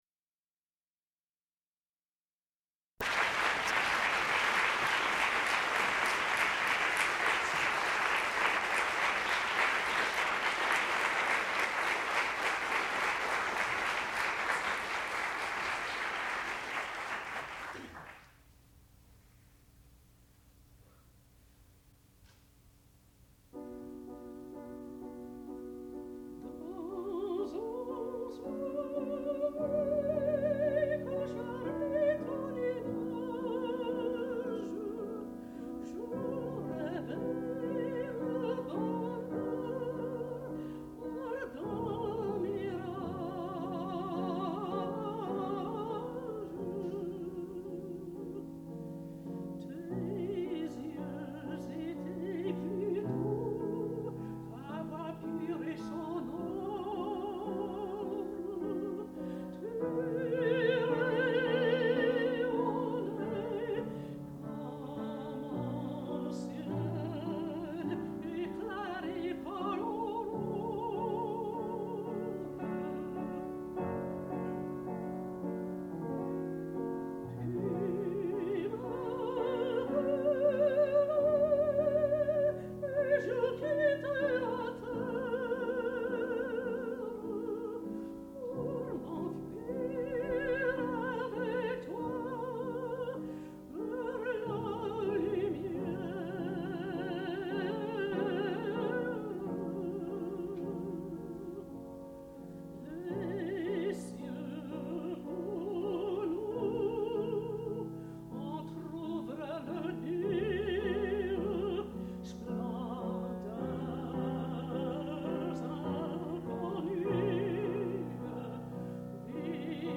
sound recording-musical
classical music
mezzo-soprano
piano